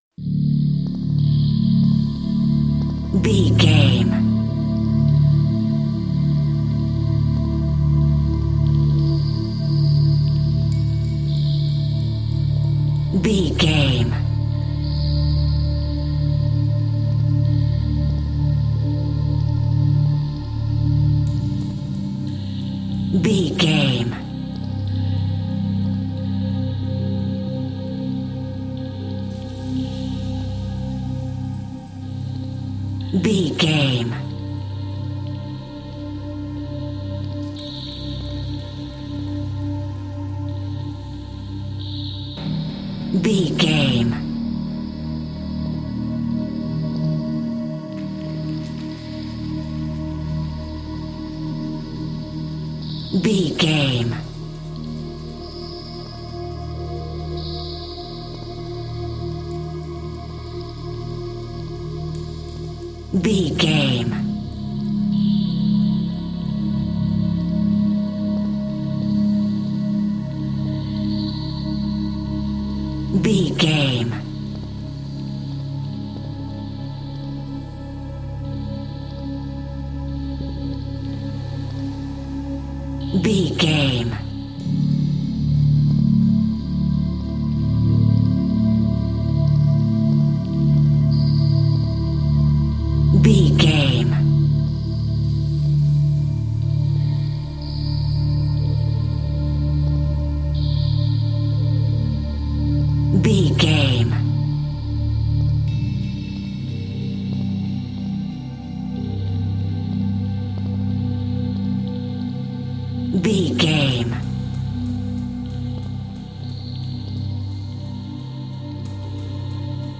Aeolian/Minor
D
percussion
synthesiser
tension
ominous
dark
suspense
haunting
creepy